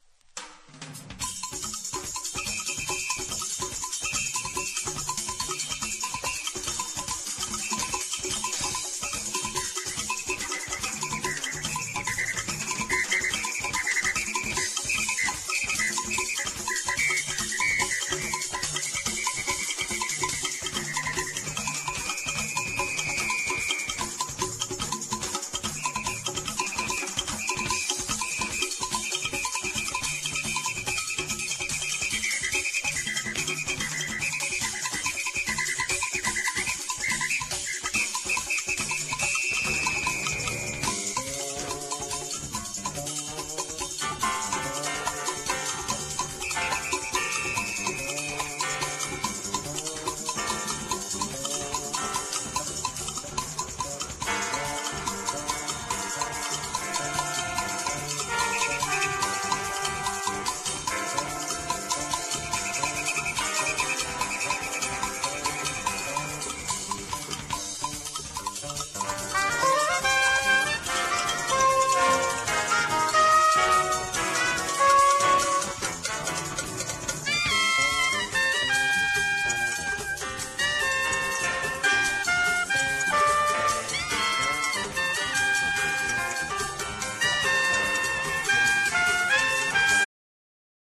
# DANCE FLOOR